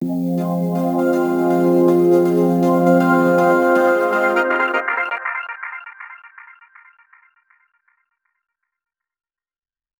sci-fi, 00s, x-files, warm, The little green men, ufo vibe, rnb, sexy, romantic, riser, fx, slow, 160 bpm
sci-fi-00s-x-files-warm-t-u7kuksnl.wav